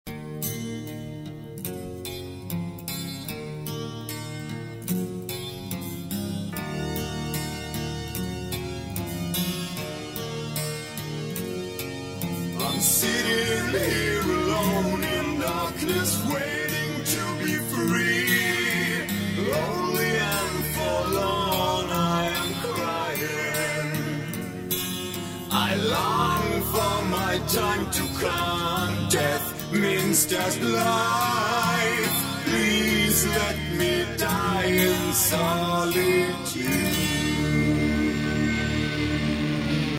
• Качество: 128, Stereo
гитара
мужской голос
спокойные
струнные
doom metal